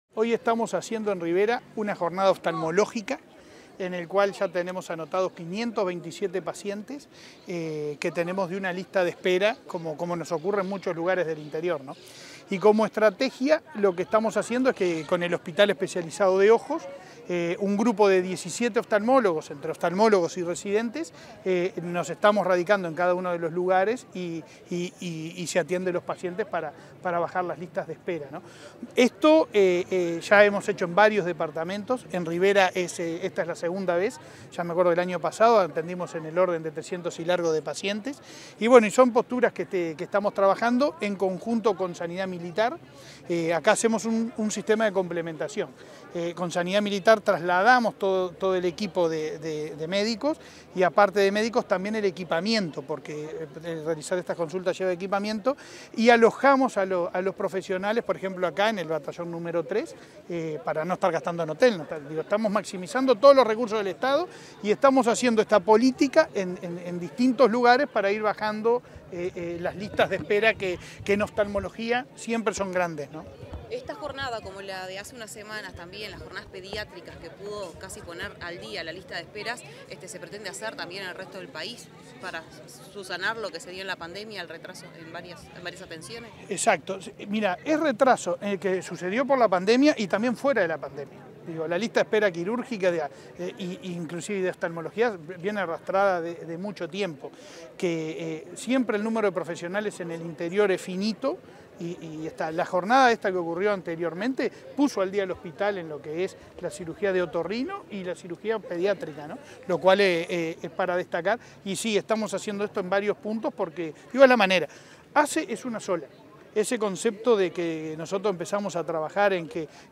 Declaraciones del presidente de ASSE, Leonardo Cipriani, en Rivera